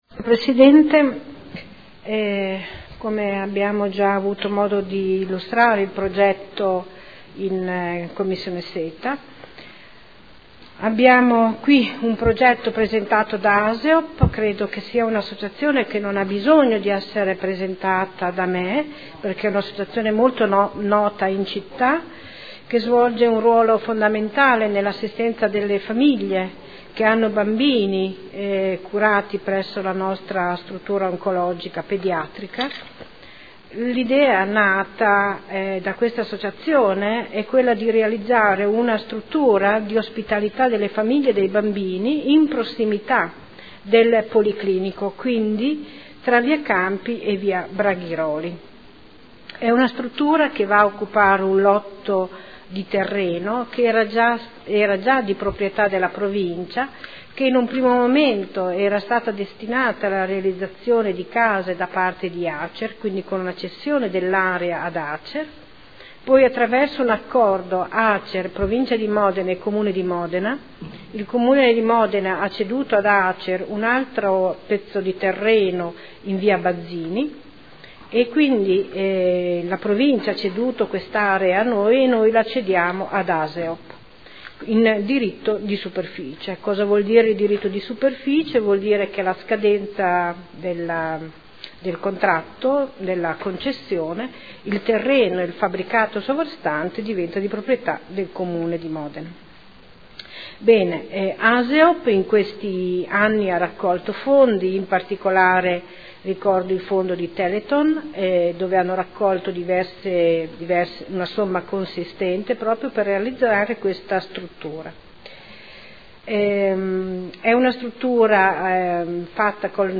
Anna Maria Vandelli — Sito Audio Consiglio Comunale